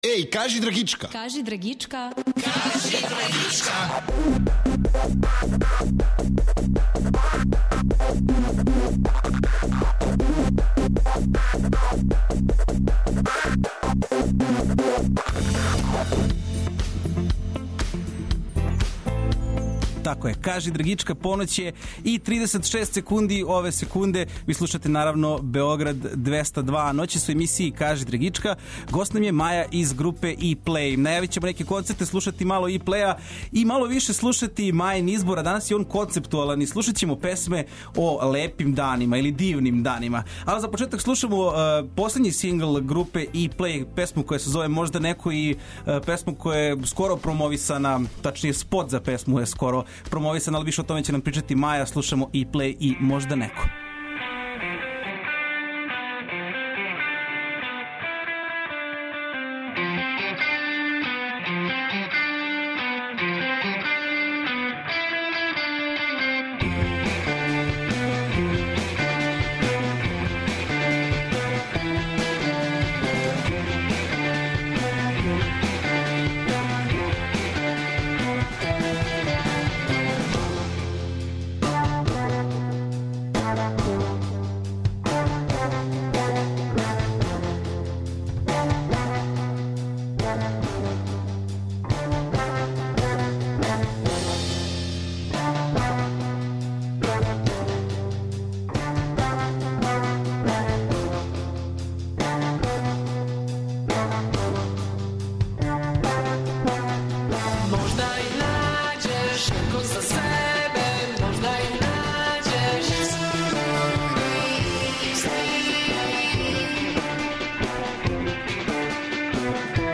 Слушаћемо њен музички избор, а попричаћемо о плановима, новим песмама, предстојећим концертима и свему актуелном.